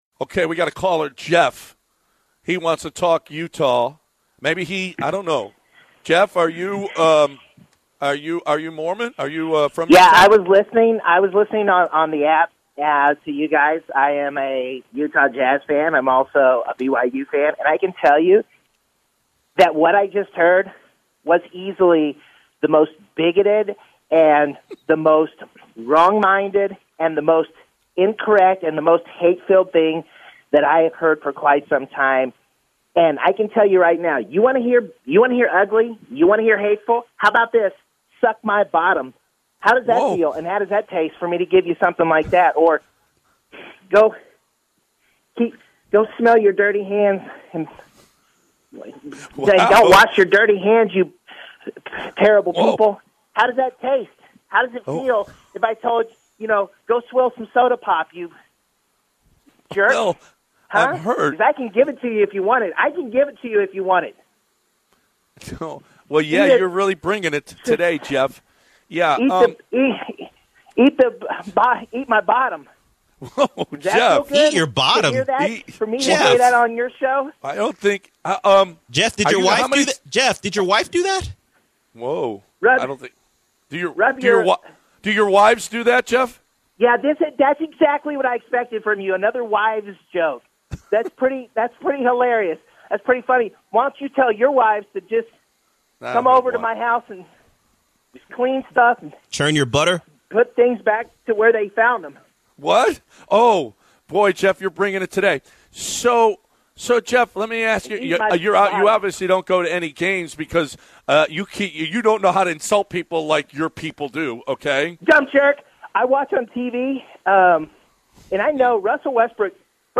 Angry Jazz Fan on The Bench